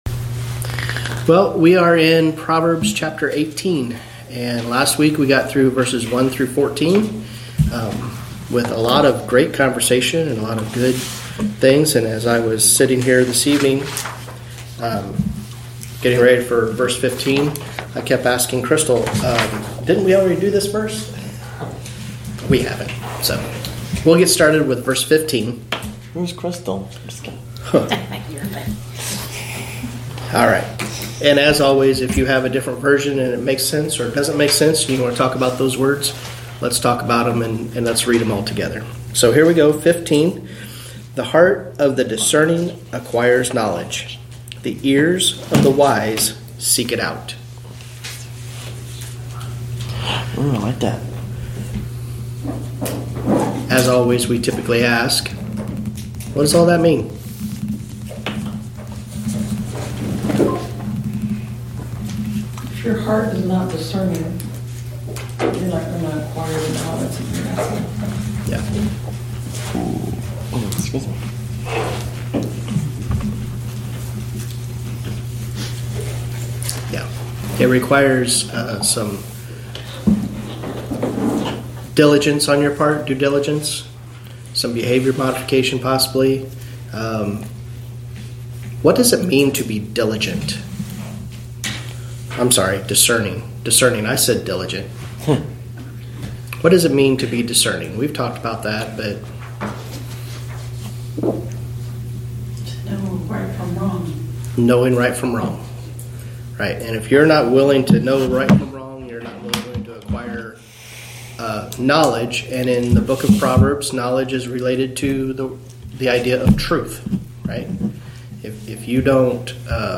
Wedensday Evening Bible Study Prov. 18:15-24